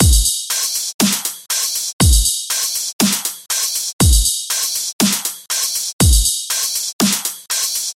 Dubstep Drums 001 120BPM
描述：ubstep Drums Loops。
Tag: 120 bpm Dubstep Loops Drum Loops 1.35 MB wav Key : Unknown Mixcraft